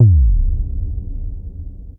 Synth Impact 22.wav